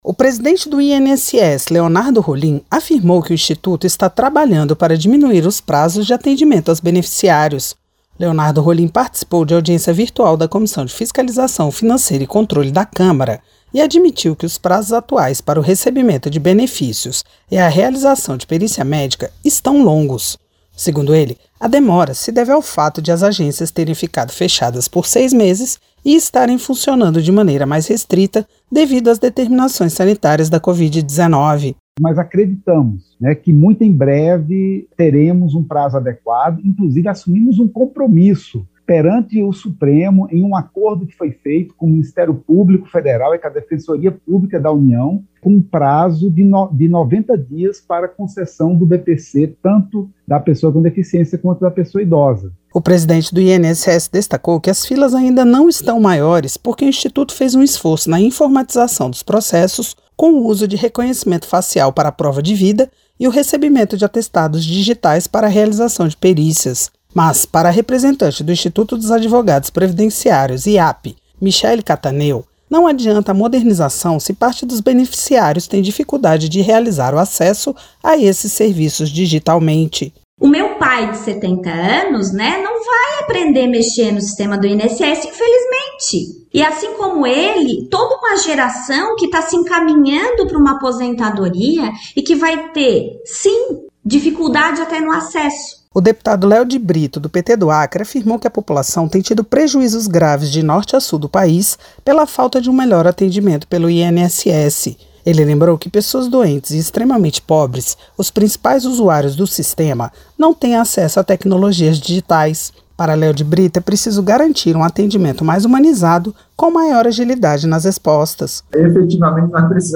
Leonardo Rolim participou de audiência virtual da Comissão de Fiscalização Financeira e Controle da Câmara nesta quinta (14), e admitiu que os prazos atuais para o recebimento de benefícios e a realização de perícia médica estão longos.